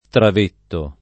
Travet [piem. trav$t; non -v$] cogn. — personaggio di V. Bersezio — per lo più con t- minusc. come nome com. («impiegatuccio»; s. m., inv.: err. il pl. travets); italianizz. qualche volta in travetto [